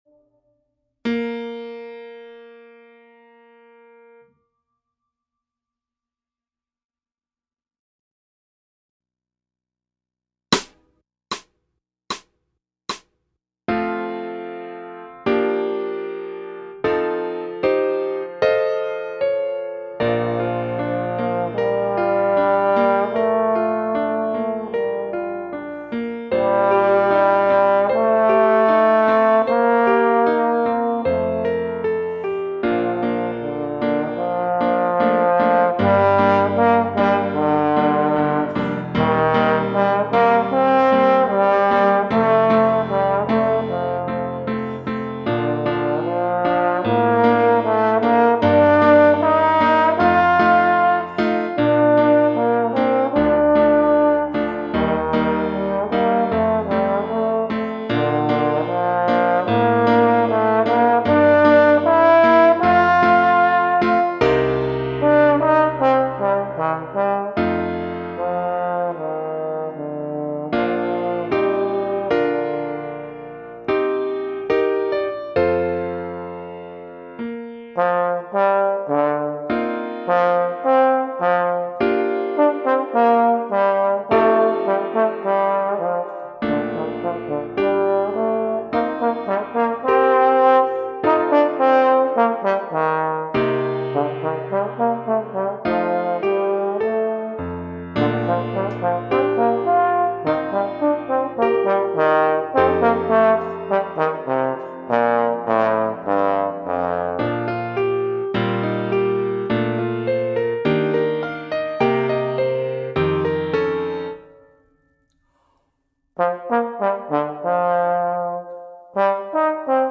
Pour trombone et piano DEGRE FIN DE CYCLE 1 Durée